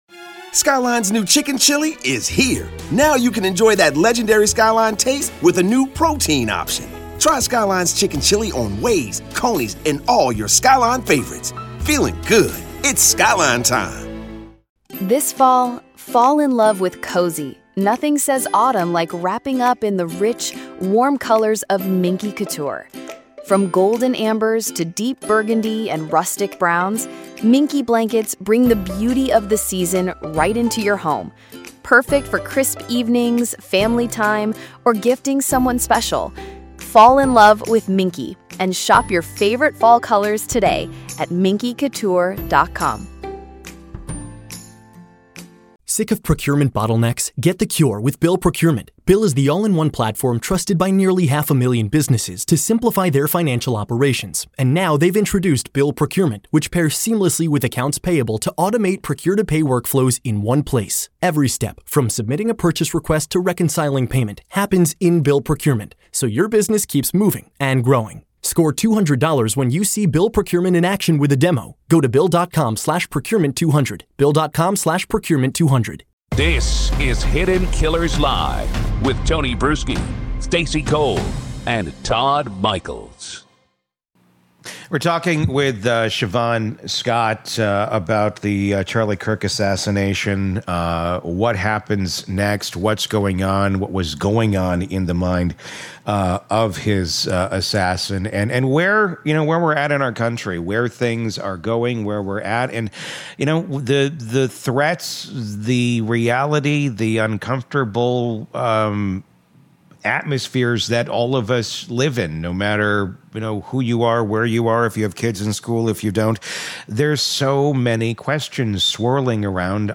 This episode pulls back the curtain on the psychology of targeted violence—and why so many communities only connect the dots when it’s already too late. 🔔 Subscribe for more true crime interviews, forensic breakdowns, and expert-driven analysis from inside the nation’s most disturbing cases.